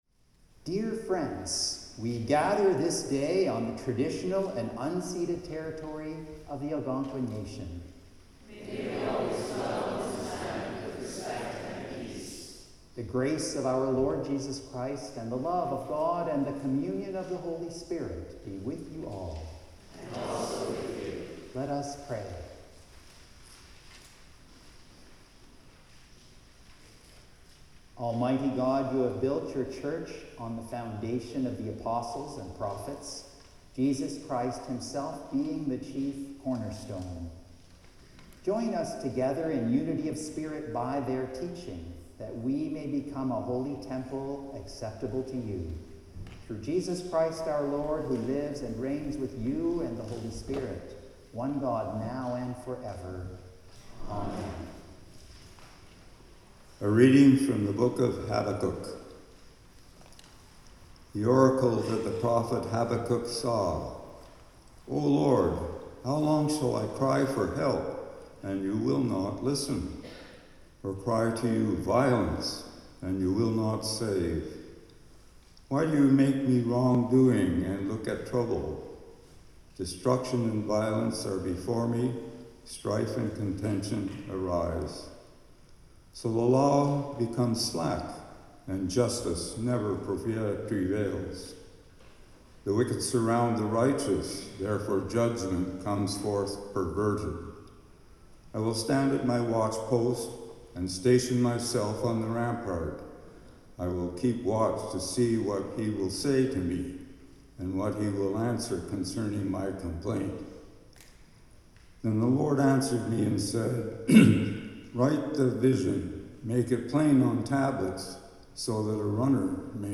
(reading in French)
The Lord’s Prayer (sung)